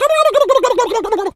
turkey_ostrich_gobble_15.wav